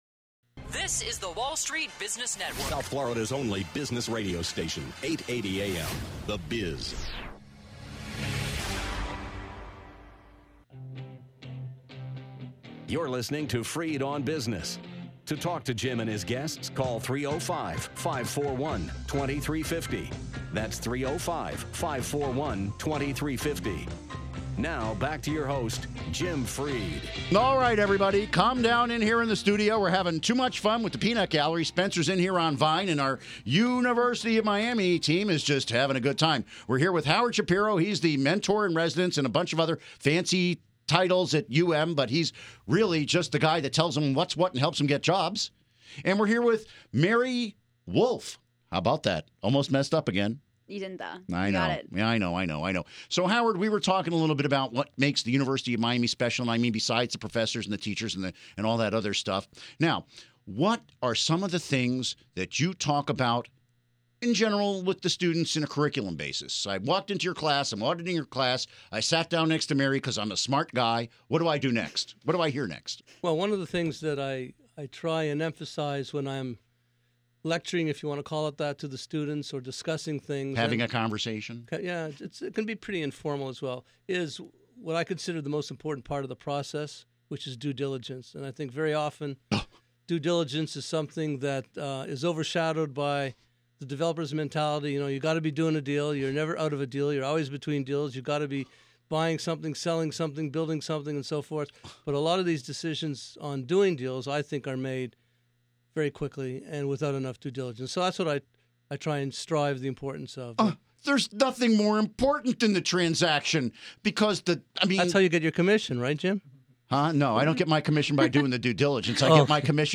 Interview Segment Episode 251: 02-13-14 (To download Part 1, right-click this link and select “Save Link As”.